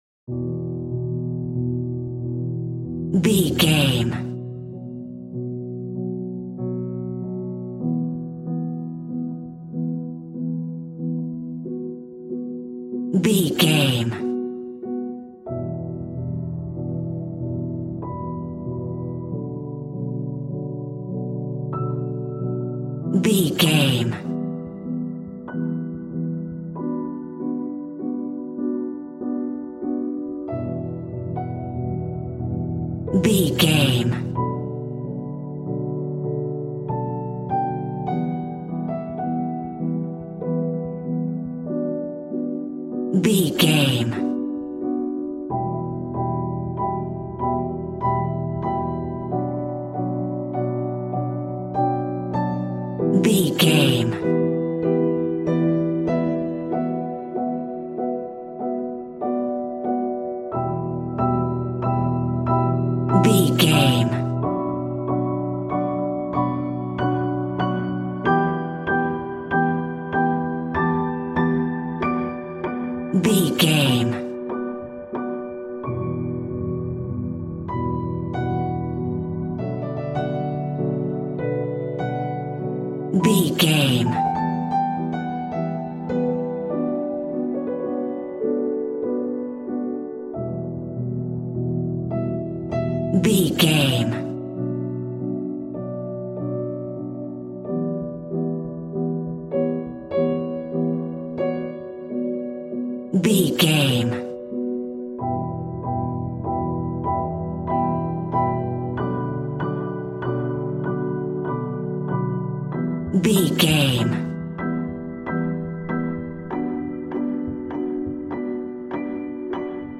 Aeolian/Minor
scary
ominous
dark
haunting
eerie
mournful
horror music
horror piano